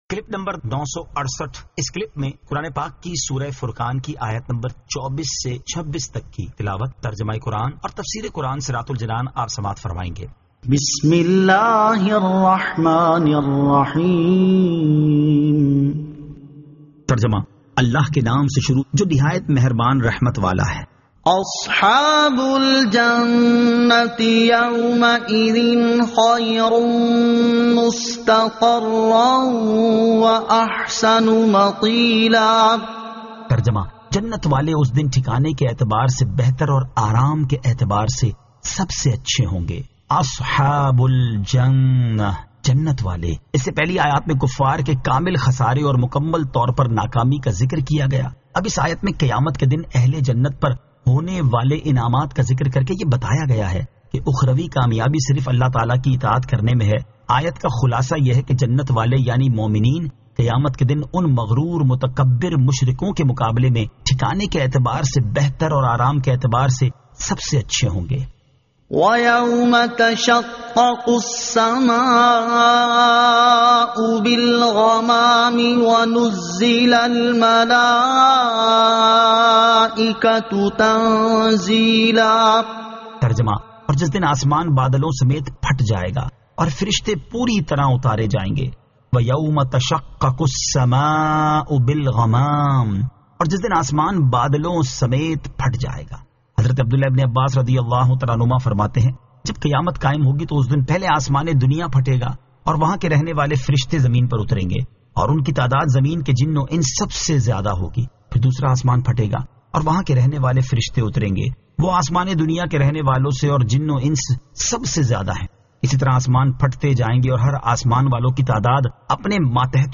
Surah Al-Furqan 24 To 26 Tilawat , Tarjama , Tafseer